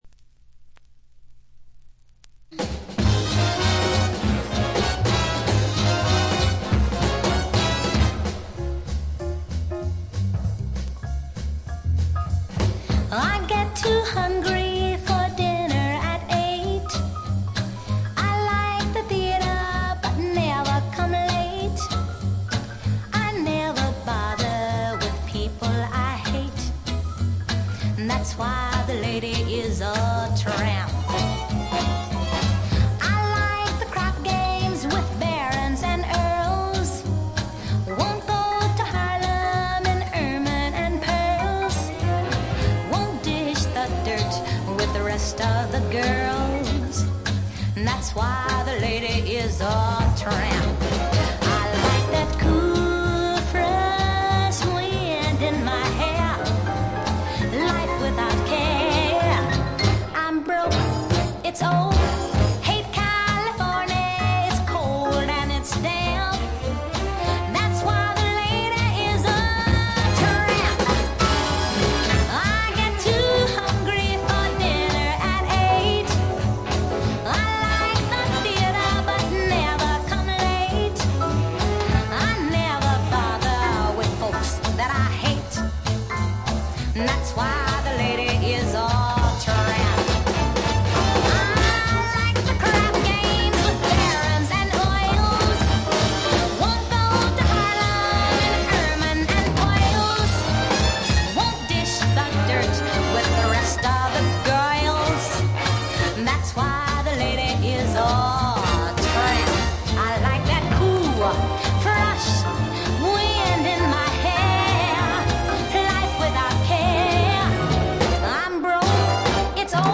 ほとんどノイズ感無し。
モノプレス。
試聴曲は現品からの取り込み音源です。